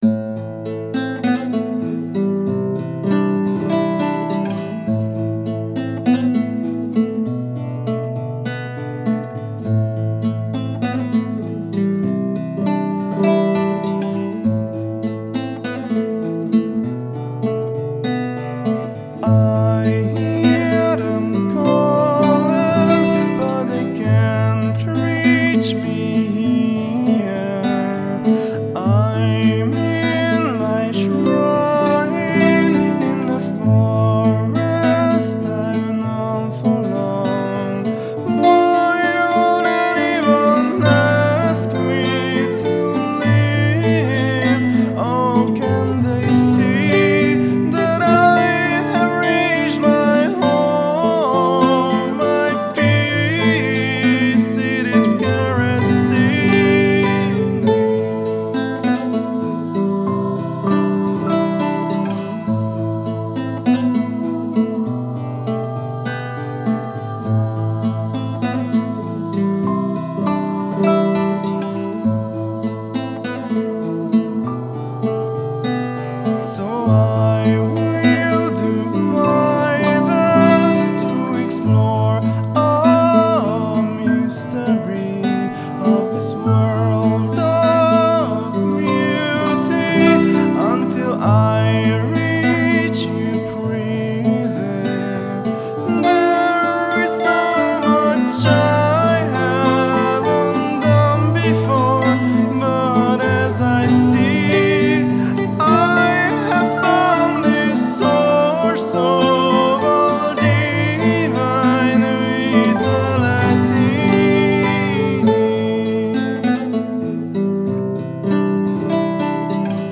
音乐风格：新民谣/中古民谣
他们的音乐如童话般的动听，以前发行的3张的电子器乐完全被弃用，新专辑只有木吉他声、笛声和男声。